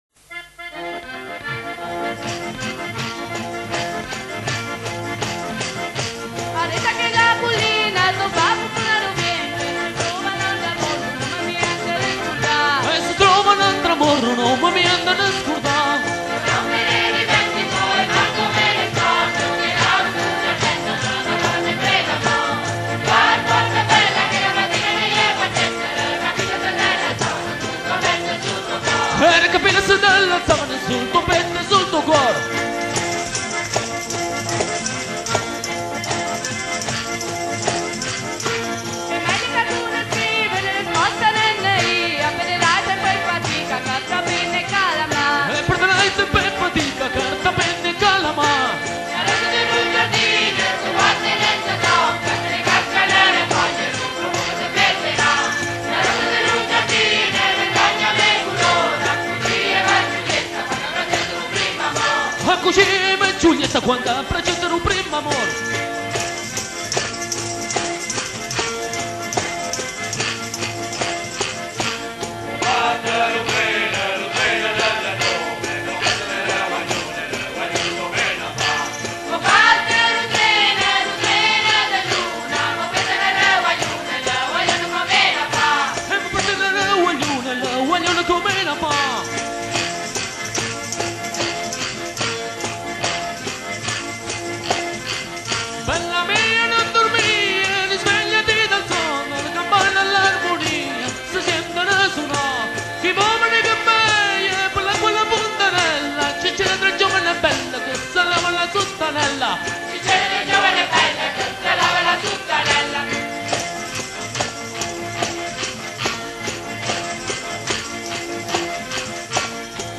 Parole e musica tradizionali
Esecuzione: Gruppo Folklorico Rintocco Molisano di Agnone